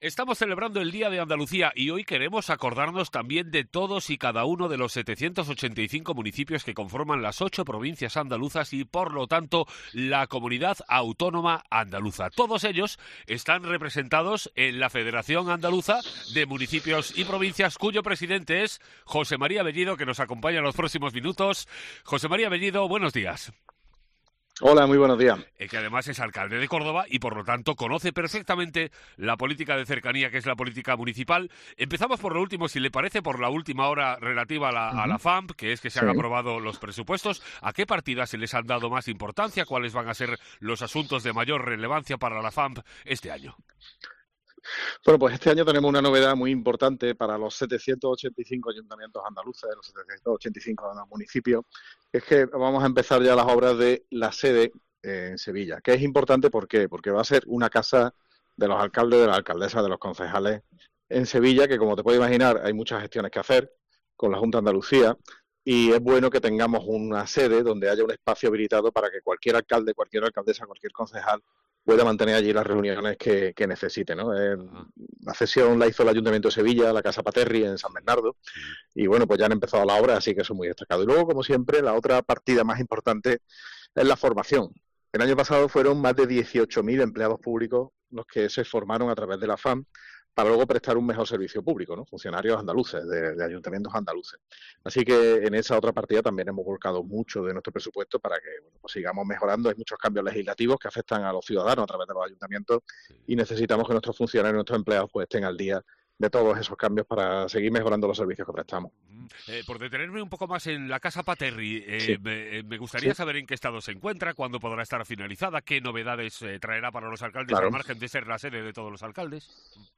entrevista-BELLIDO-28-F.mp3